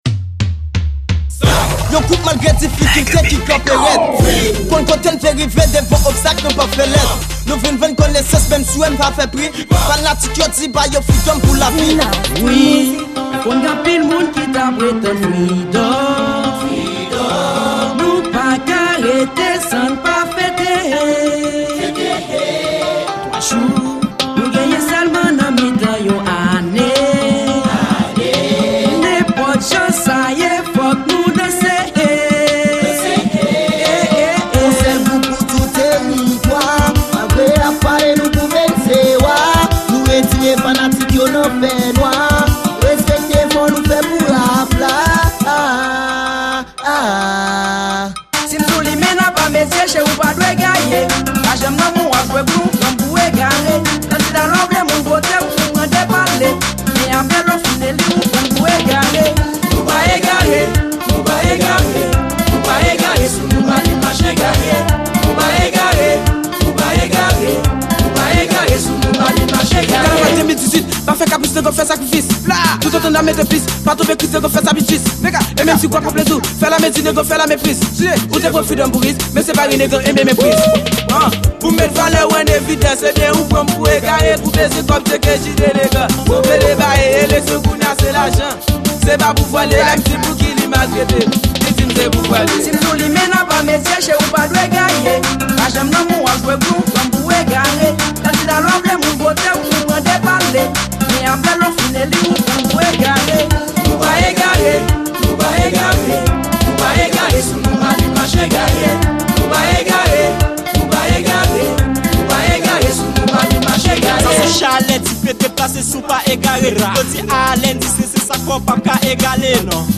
Genre: KANAVAL